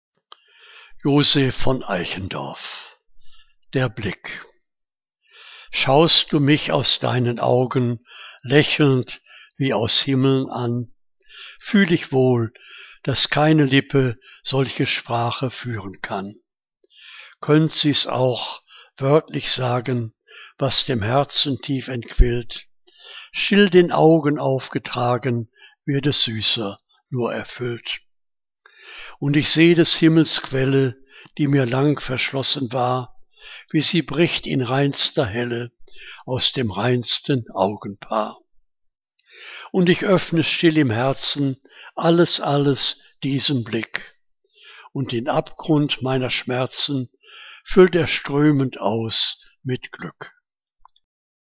Liebeslyrik deutscher Dichter und Dichterinnen - gesprochen (Eichendorff)